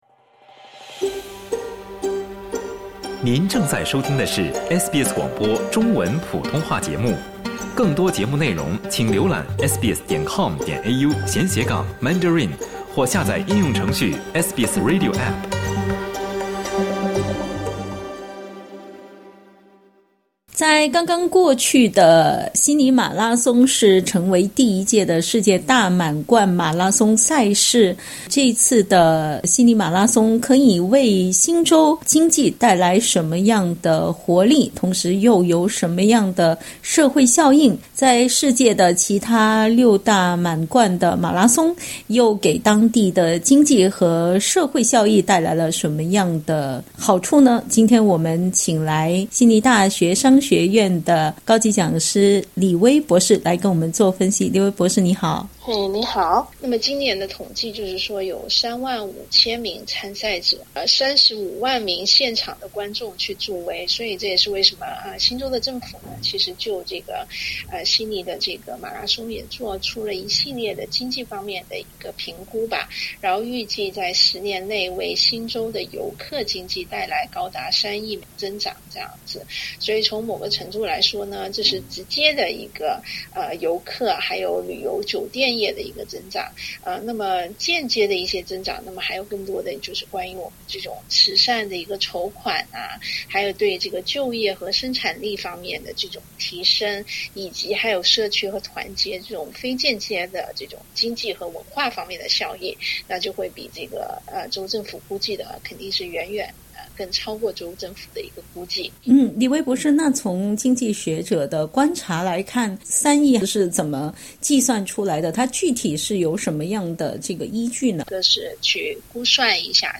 （点击音频收听详细采访） 8月31日，悉尼举办了悉尼马拉松赛事，这是悉尼马拉松正式成为世界第七大马拉松赛事的第一次比赛，参赛人数为3.5万人，观众达到30多万人。